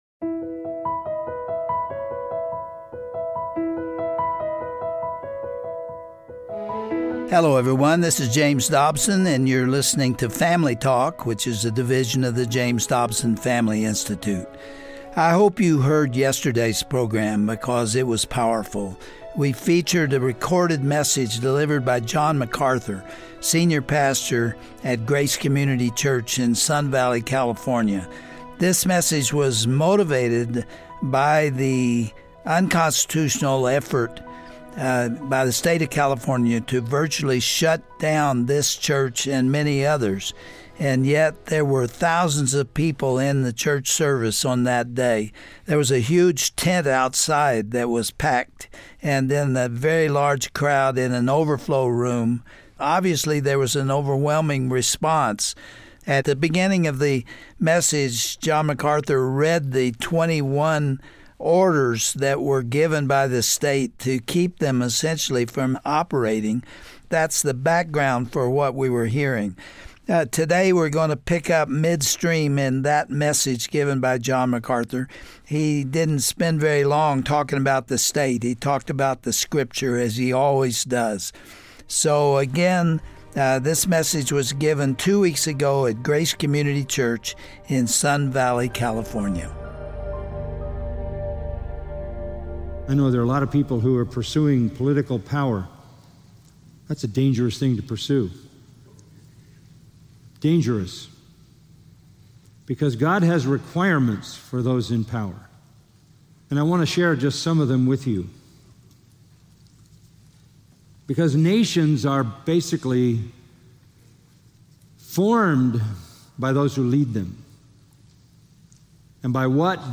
What does God expect from those who hold political office in America? Pastor John MacArthur explores the concept of God-ordained civic leadership and points to godly characteristics of leaders throughout Scripture.
Host Dr. James Dobson